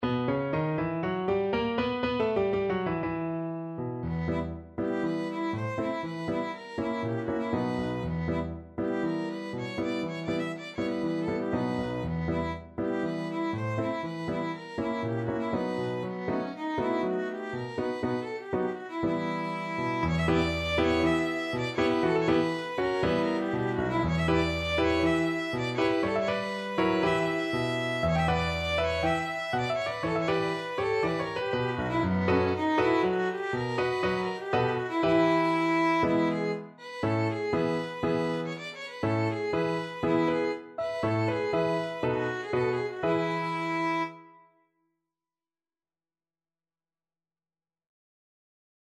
Varshaver Freylekhs (Klezmer)
Violin
B minor (Sounding Pitch) (View more B minor Music for Violin )
Allegro (View more music marked Allegro)
2/4 (View more 2/4 Music)
varshaver_freylekhs_VLN.mp3